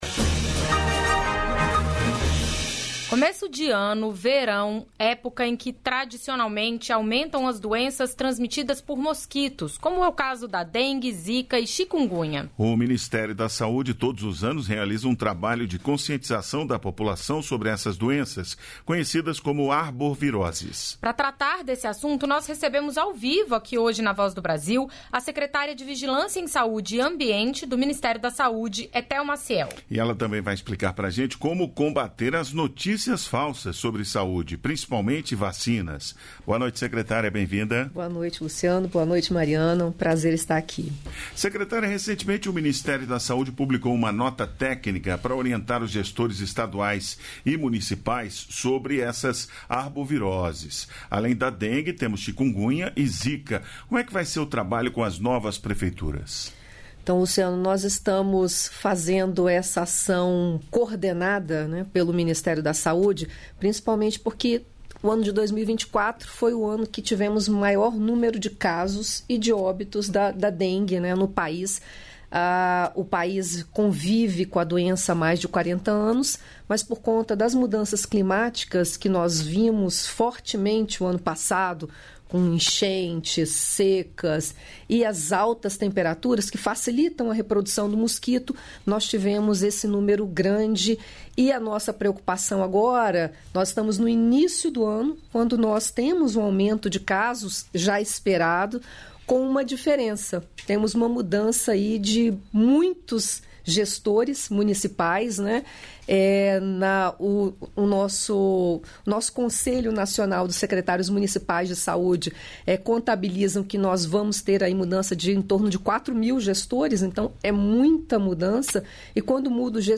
Entrevistas da Voz Waldez Góes, ministro da Integração e do Desenvolvimento Regional Ministro detalhou ações para a recuperação do Rio Grande do Sul após fortes chuvas atingirem o estado.